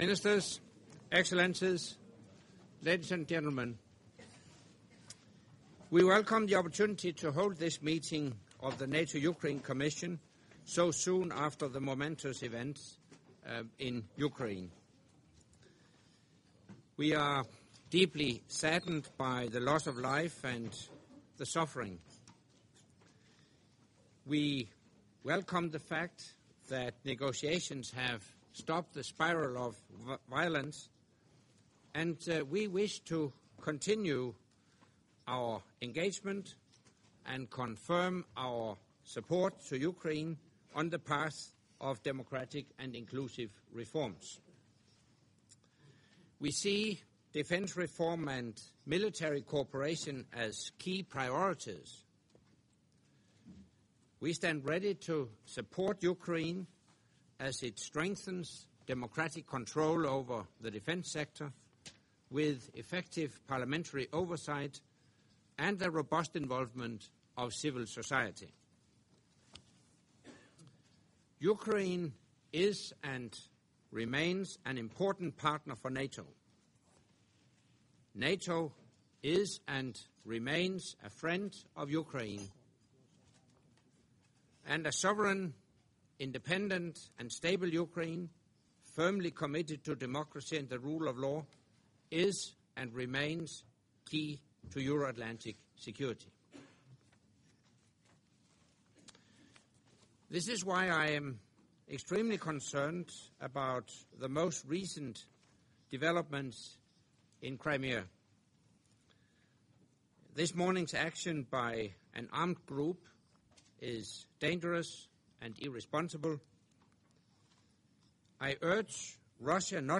Opening remarks by NATO Secretary General Anders Fogh Rasmussen at the NATO-Ukraine Commission in Defence Ministers’ Session